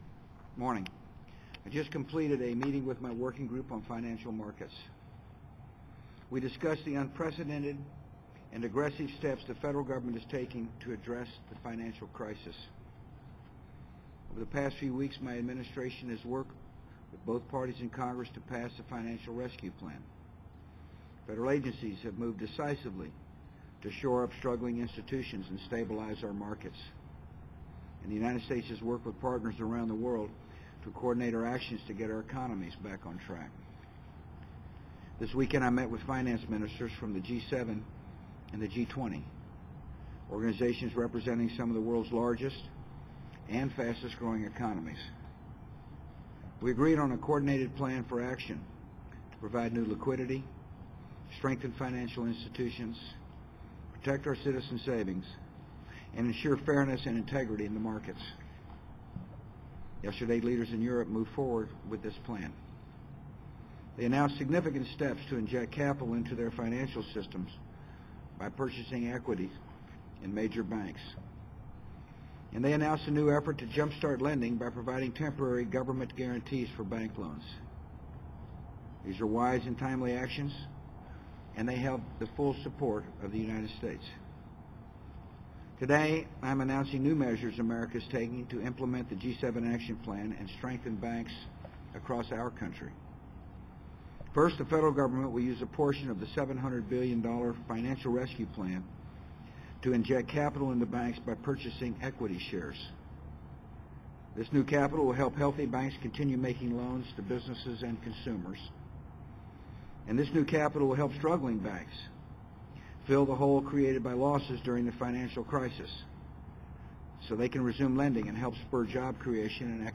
President George W. Bush discusses the economy in the White House Rose Garden. Bush describes his work along with Congress, federal agencies, and the G7 and G20 organizations to pass a financial rescue plan. Bush goes through the rescue plan's steps, including how the requested $700 billion will inject capital into banks by purchasing equity shares.